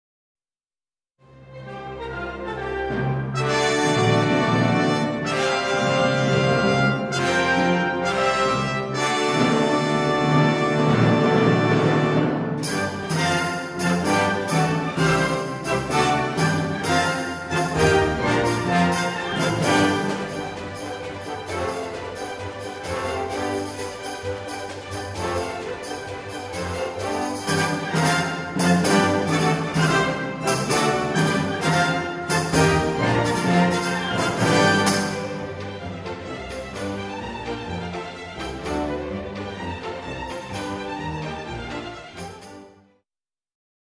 A haunting nocturne for strings and solo horn.